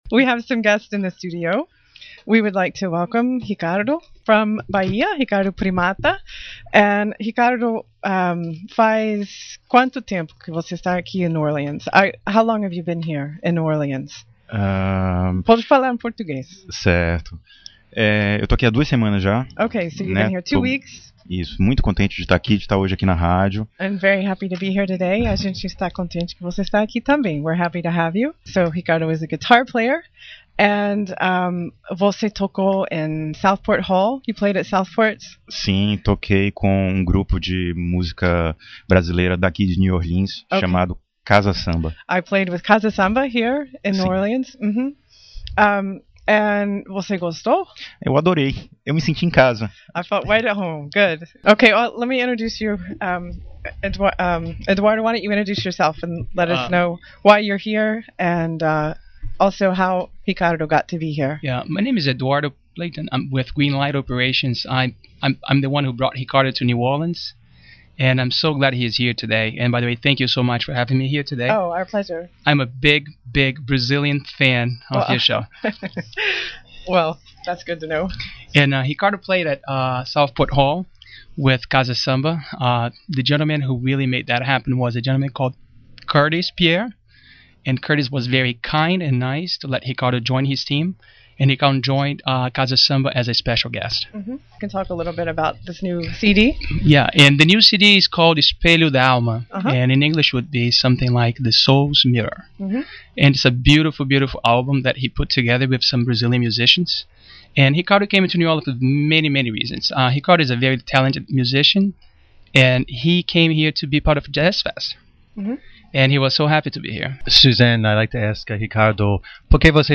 2010 - WWOZ radio interview (New Orleans - USA)
Entrevista-para-Radio-WWOZ.mp3